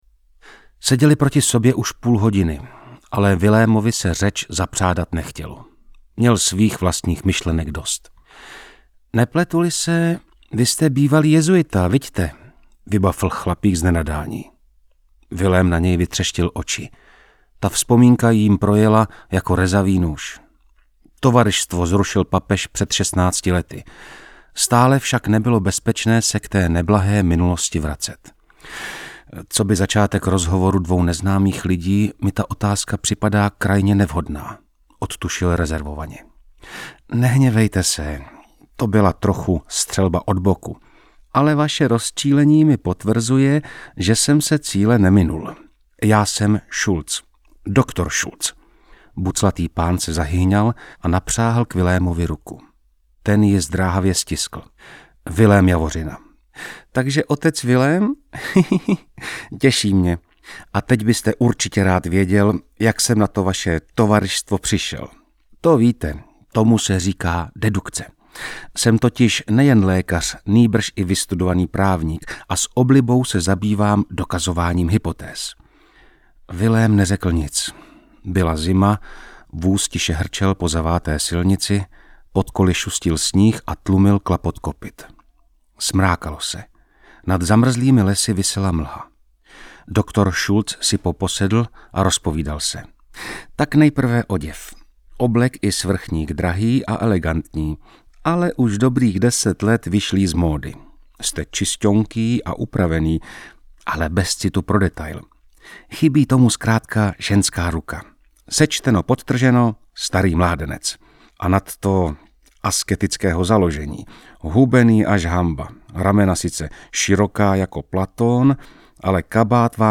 Audiokniha
Čte: Martin Pechlát